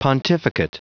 Prononciation du mot pontificate en anglais (fichier audio)
Prononciation du mot : pontificate